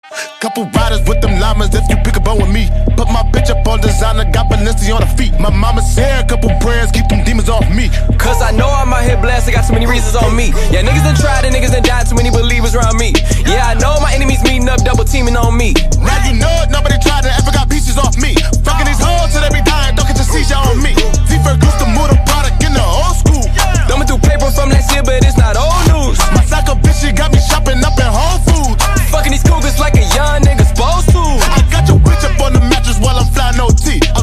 Ringtones Category: Rap - Hip Hop